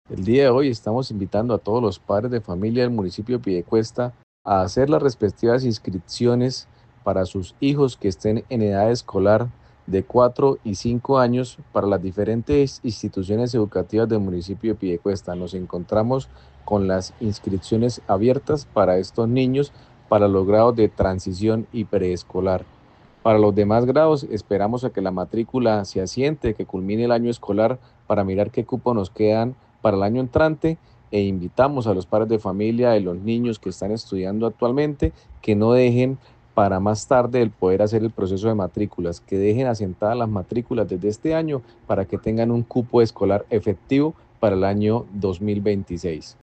Javier Gerardo Vásquez, secretario de Educación de Piedecuesta